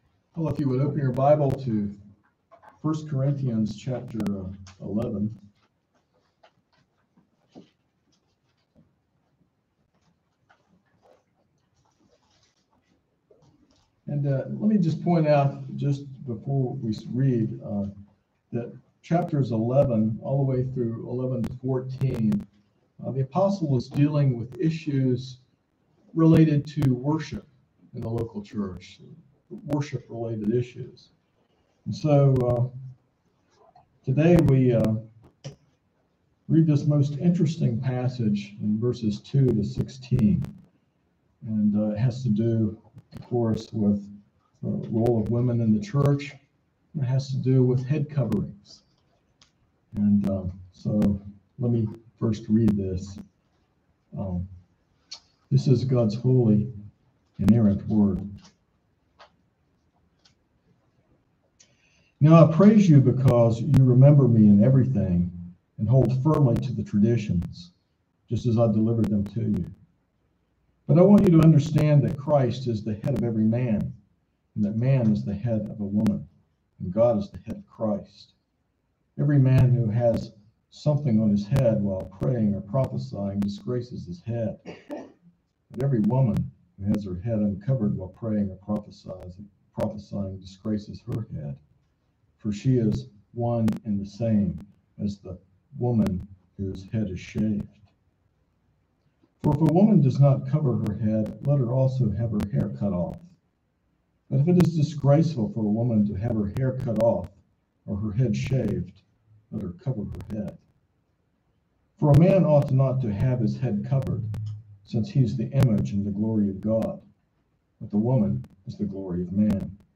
This sermon explores 1 Corinthians 11:2-16, discussing the meaning of head coverings and their significance as a symbol of God’s established order and authority in worship. It emphasizes integrity in worship, reflecting God’s nature, creation, and the witness of angels.